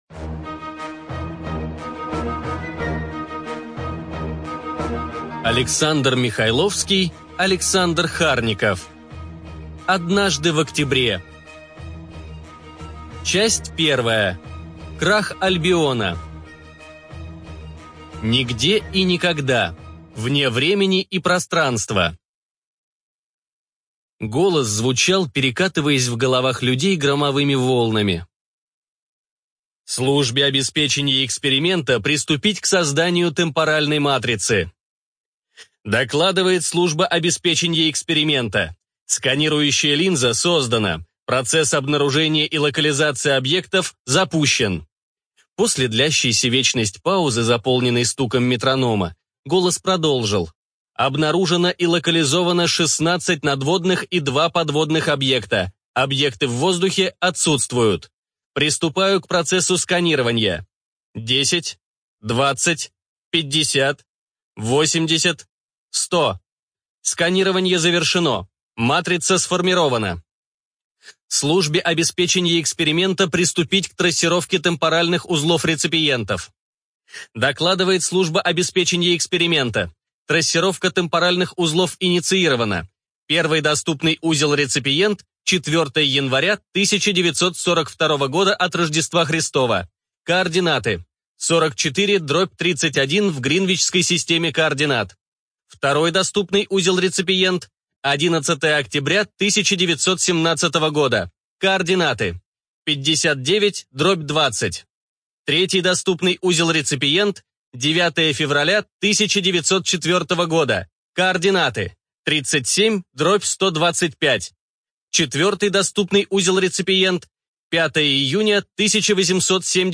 ЖанрФантастика, Альтернативная история